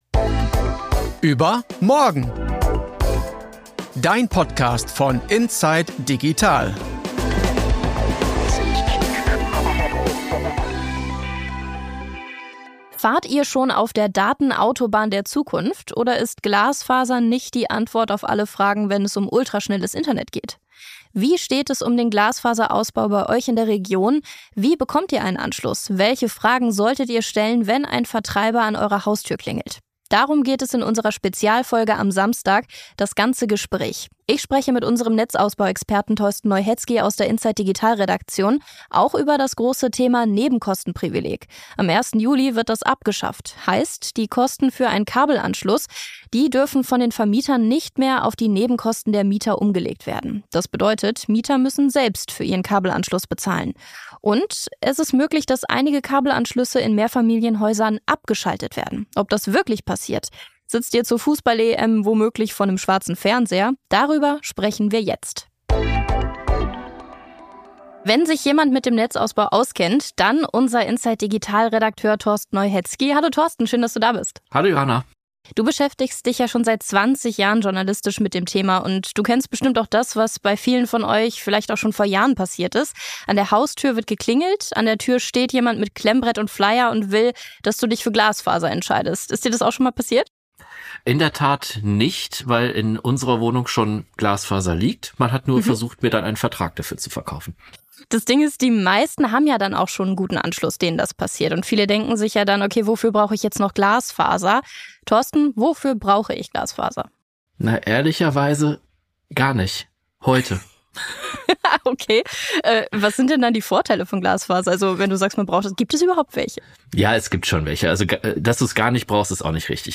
Das Gespräch aus dem Podcast überMORGEN jetzt in voller Länge!